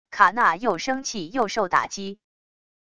卡娜又生气又受打击wav音频